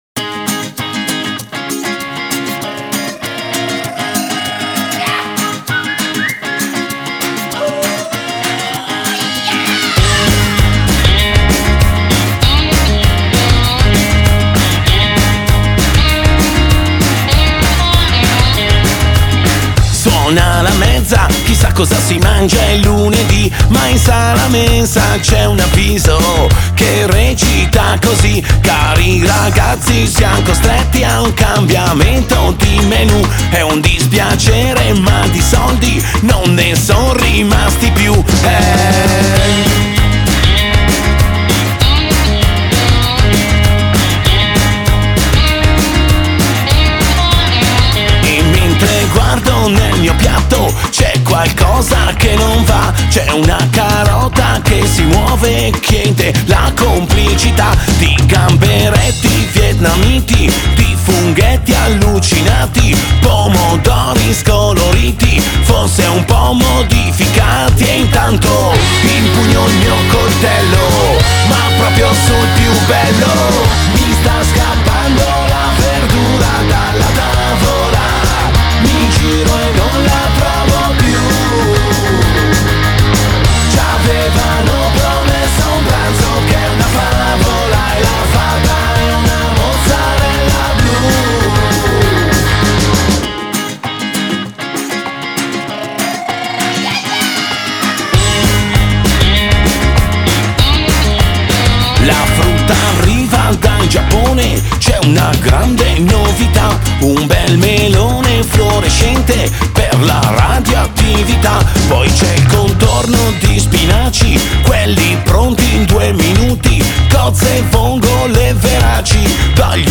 Genre: Rock, Alternative, Punk-Ska